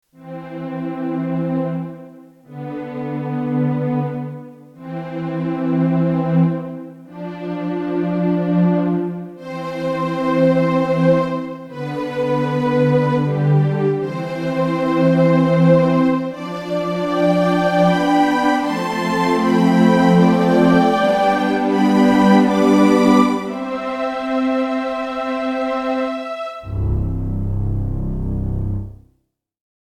【589KB　0:30】イメージ：優雅